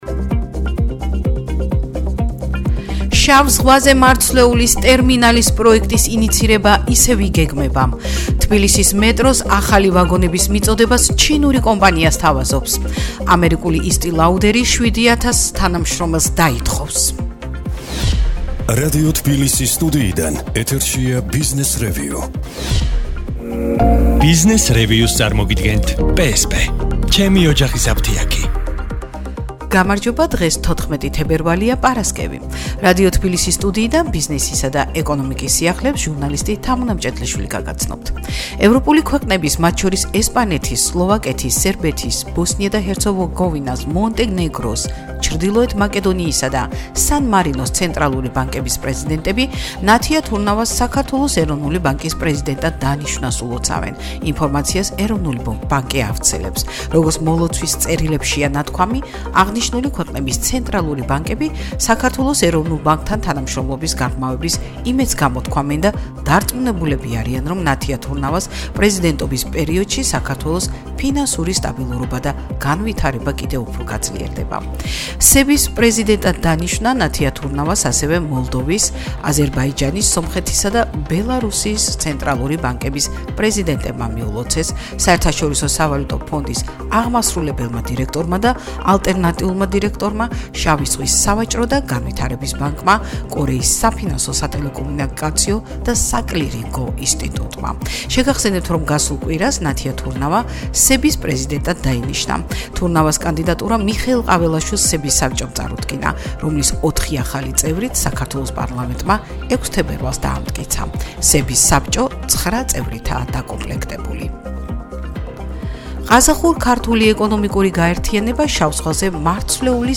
ახალი ამბები ეკონომიკასა და ბიზნესზე